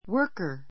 wə́ː r kə r ワ ～カ